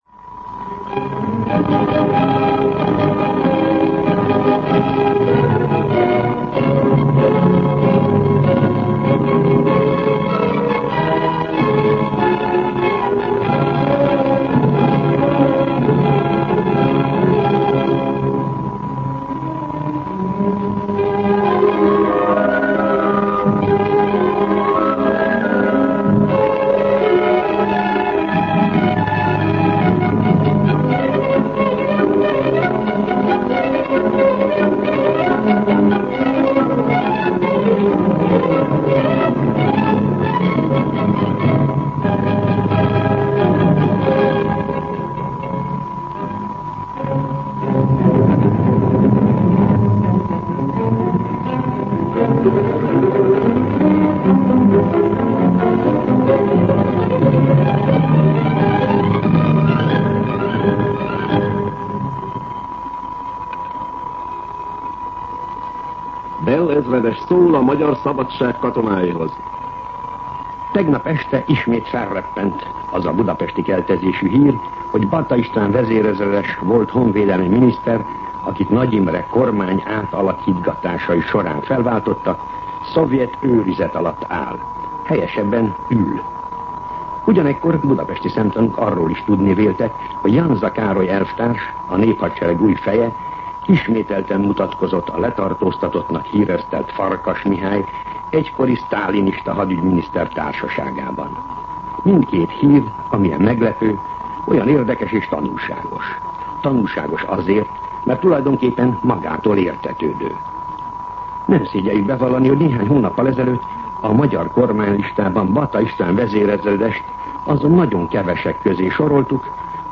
MűsorkategóriaKommentár
Megjegyzés[képviseli] hanghiba